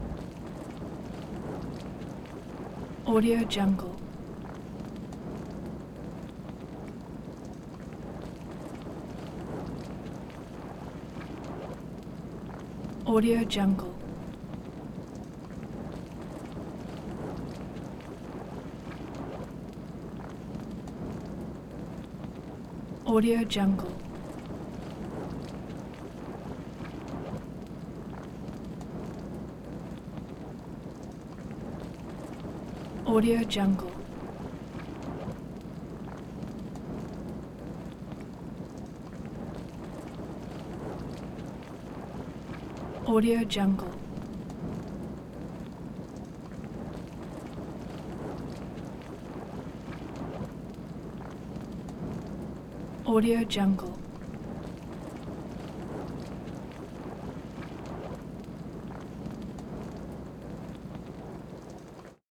دانلود افکت صدای آتش مشعل
افکت صدای آتش مشعل یک گزینه عالی برای هر پروژه ای است که به صداهای طبیعت و جنبه های دیگر مانند آتش، مشعل چوبی و شعله نیاز دارد.
Sample rate 16-Bit Stereo, 44.1 kHz